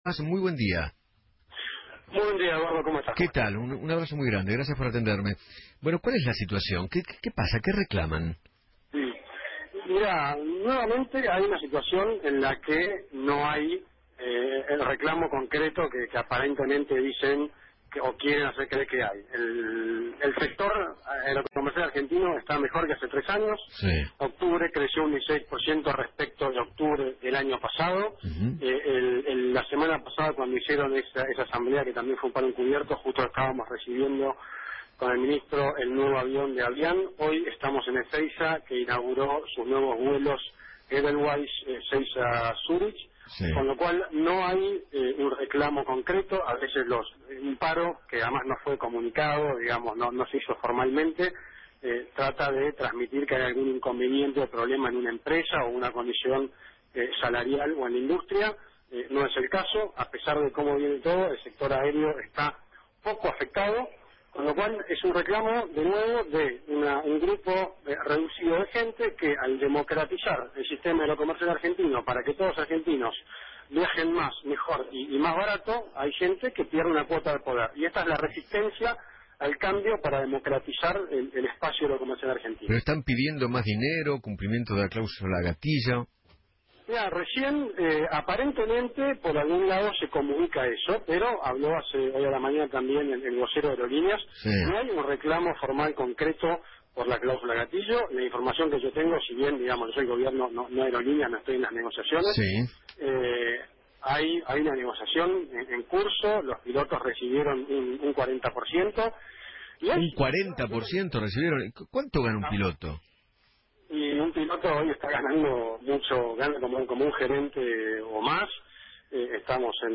Tomás Instausti, titular de la Administración Nacional de Aviación, habló en Feinmann 910 y dijo que  “Hay una situación que no tiene un reclamo concreto, el sector aerocomercial está mejor. La semana pasada también hicieron asambleas como un paro encubierto cuando con el Ministro recibíamos un avión de Avianca, hoy inauguró su nuevo vuelo en Ezeiza Edelweiss”